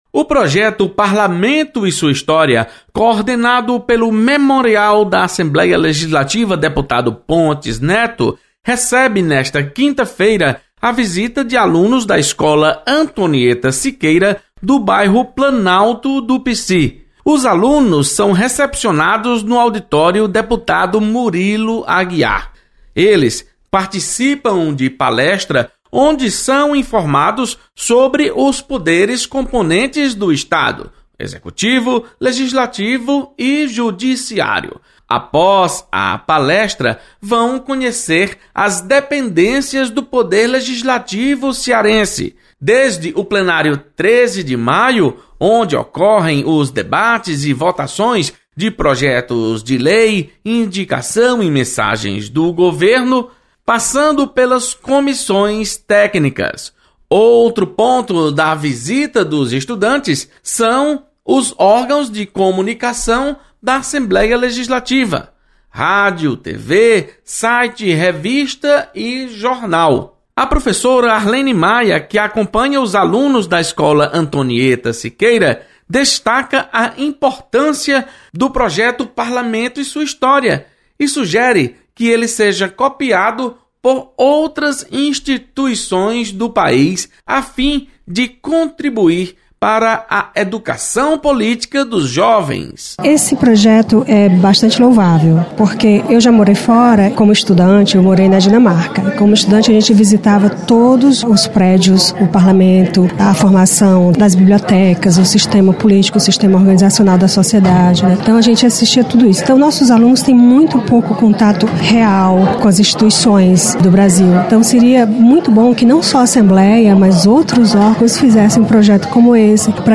Memorial Pontes Neto recebe alunos da Escola Antonieta Siqueira. Repórter